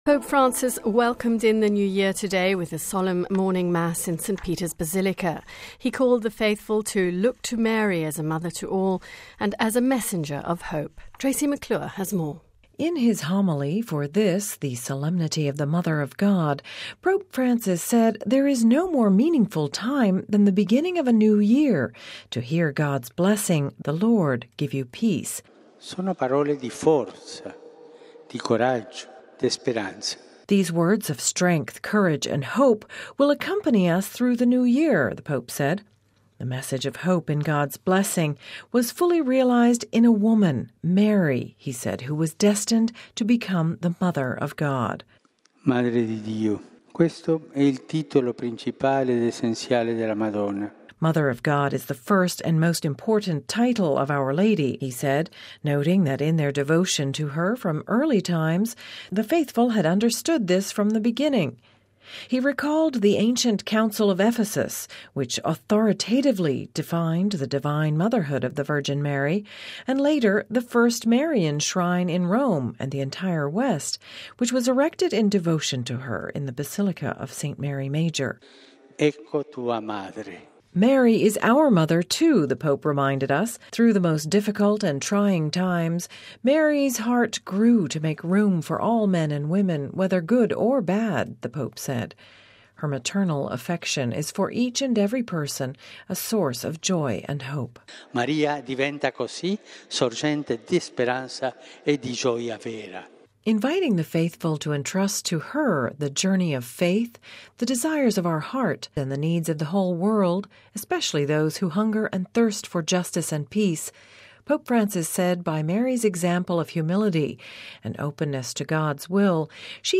(Vatican Radio) Pope Francis welcomed the new year Wednesday with a solemn morning mass in St. Peter’s Basilica, calling the faithful to look to Mary as a Mother to all and messenger of hope.